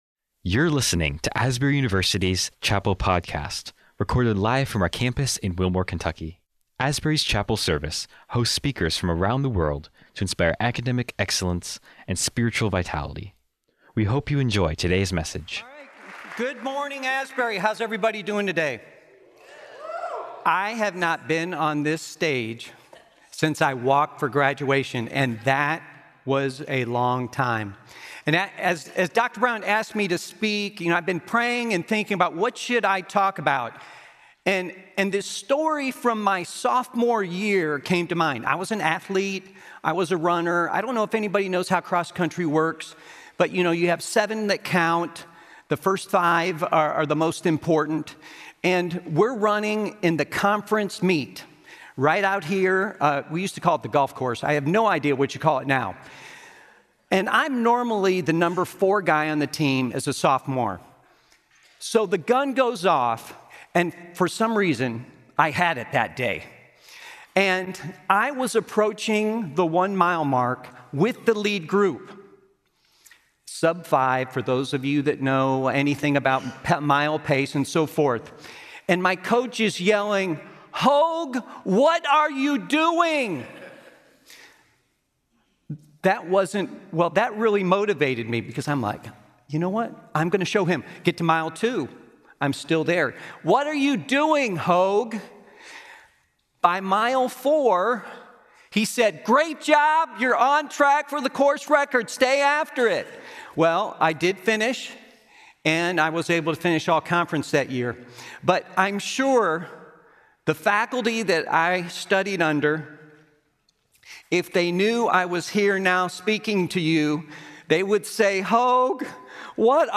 Asbury University Chapel